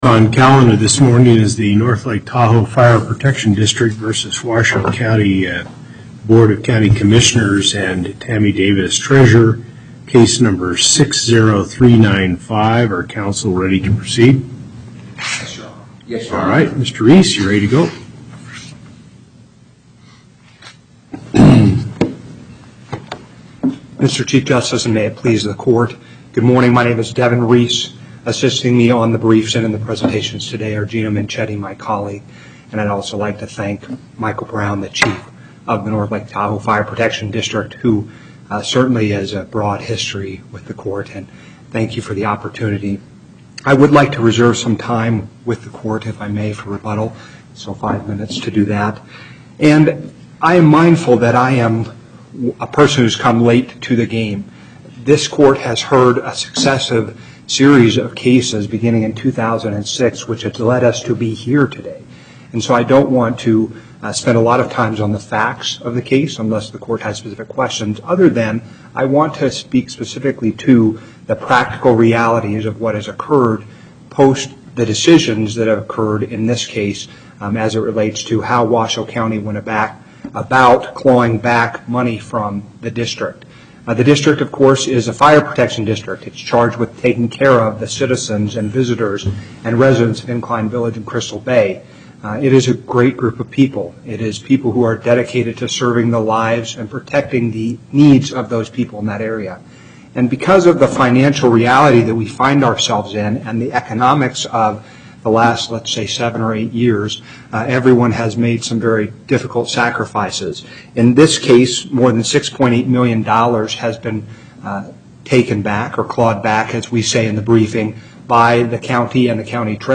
Location: Carson City Before the NNP13: Hardesty, Parraguirre and Cherry